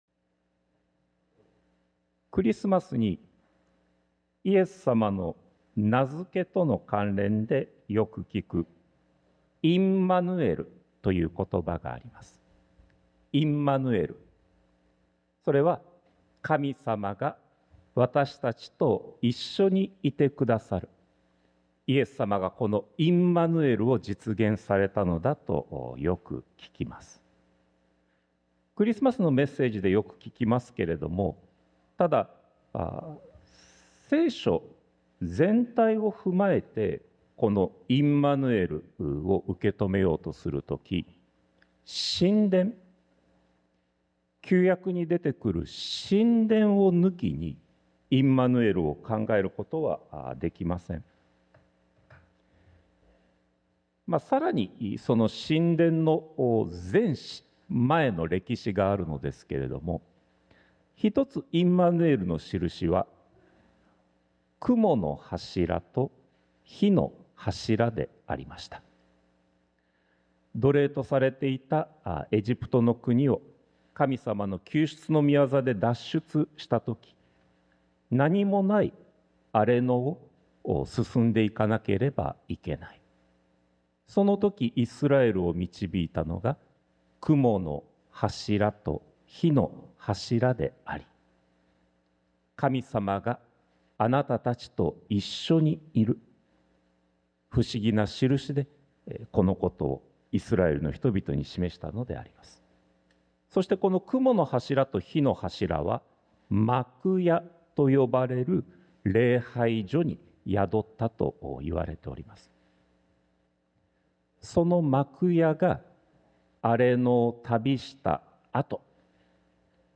sermon-2025-02-02